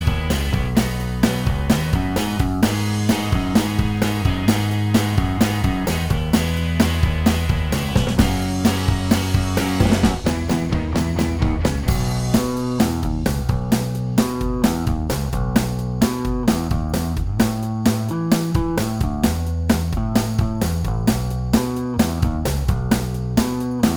Minus All Guitars Indie / Alternative 3:44 Buy £1.50